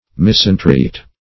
Misentreat \Mis`en*treat"\